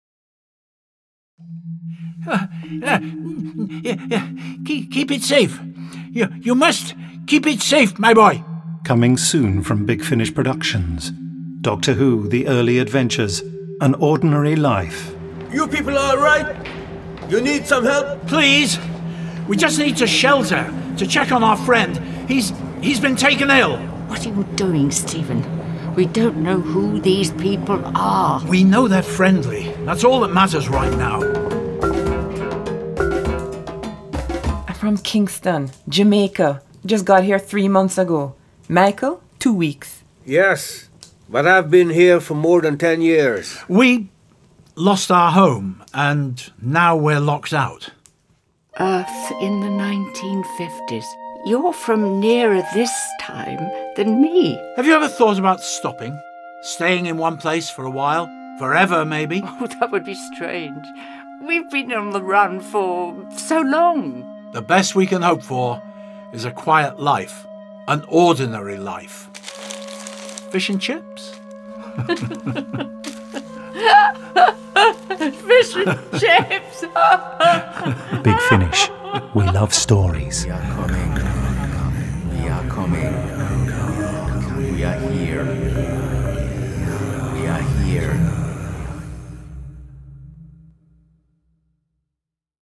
Starring Peter Purves Jean Marsh